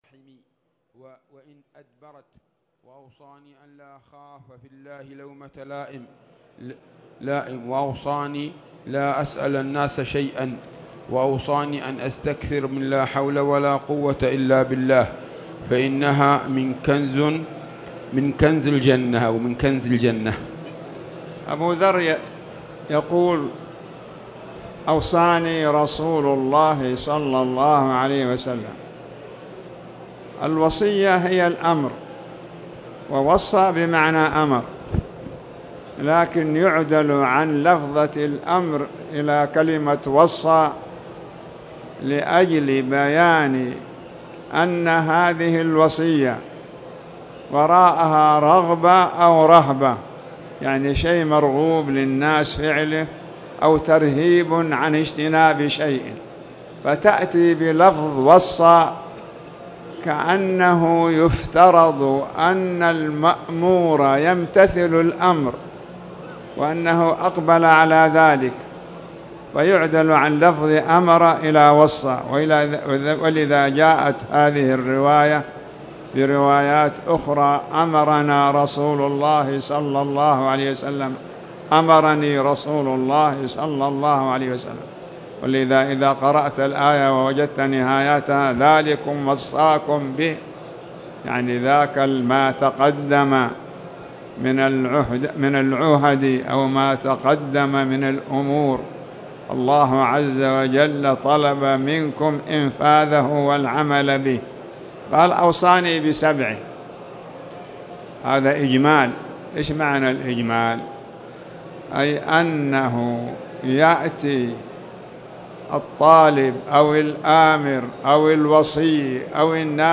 تاريخ النشر ٢٧ جمادى الآخرة ١٤٣٨ هـ المكان: المسجد الحرام الشيخ